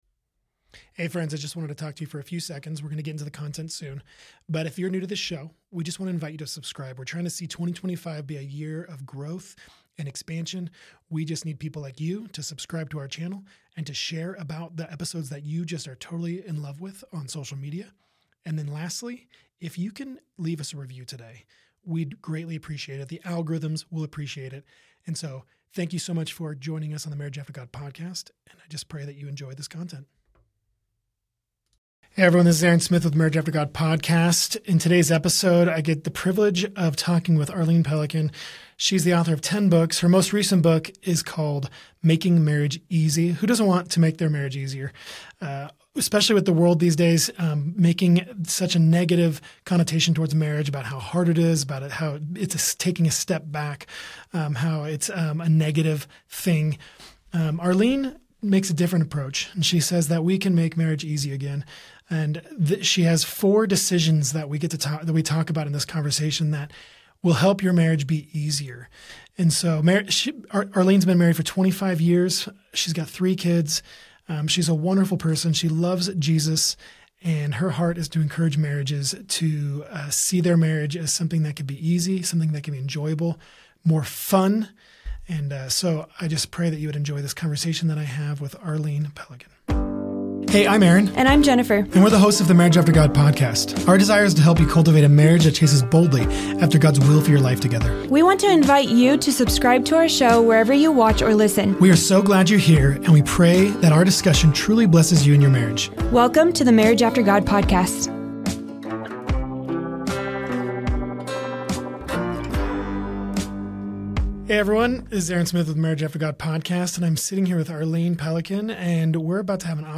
In this insightful conversation, we explore: